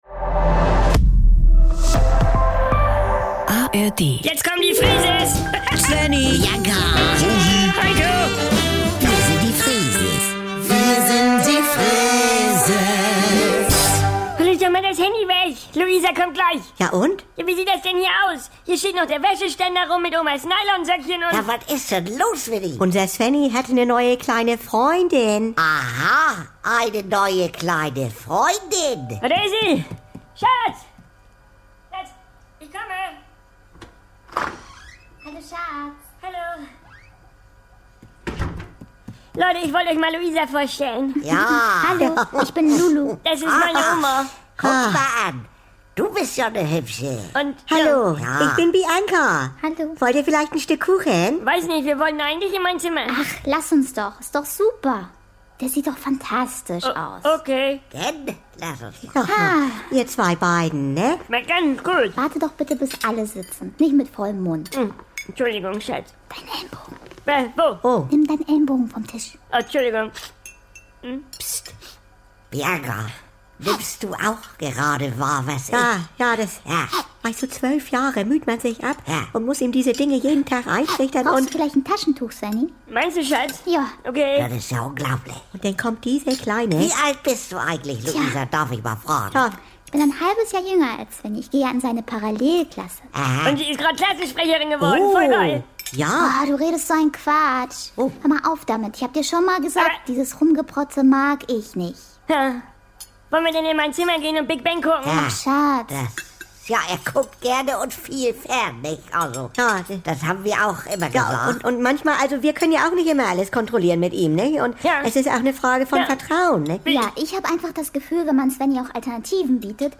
"Wir sind die Freeses" um 7.17 Uhr als Best-Of - und jederzeit verfügbar in den NDR 2 Comedy Highlights.